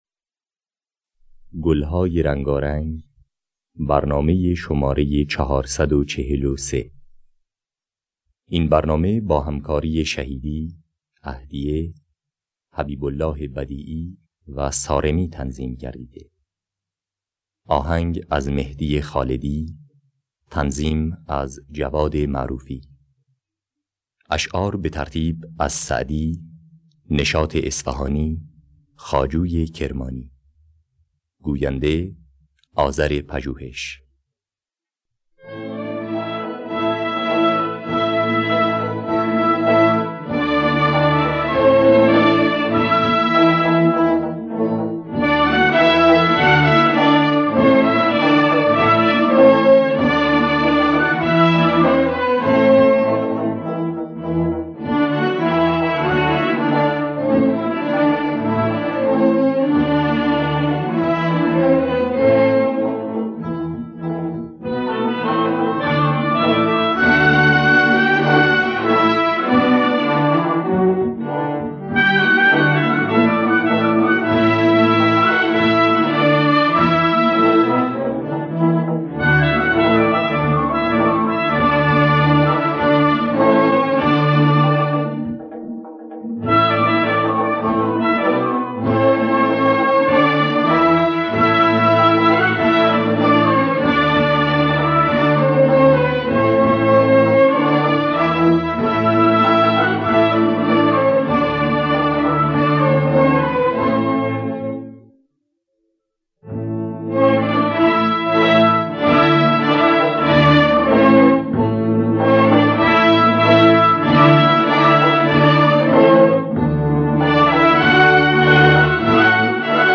گلهای رنگارنگ ۴۴۳ - ابوعطا